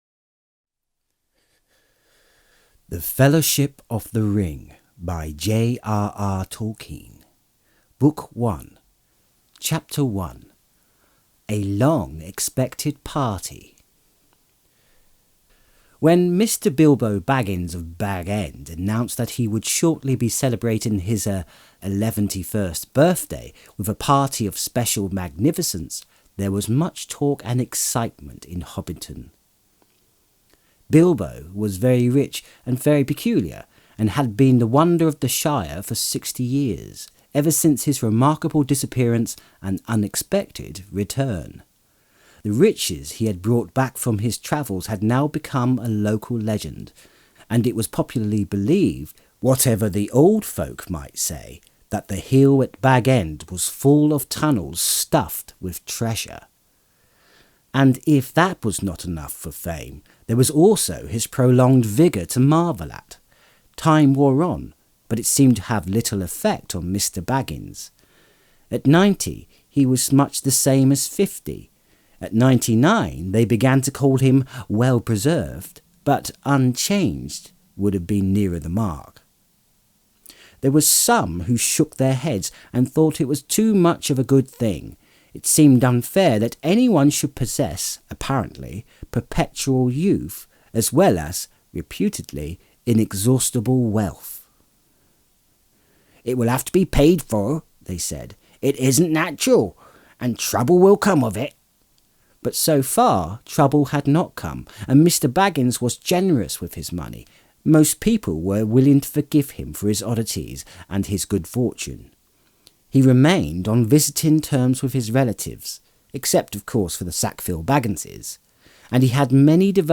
Lord Of The Rings And Hobbit Audiobooks (J.R.R. Tolkien)!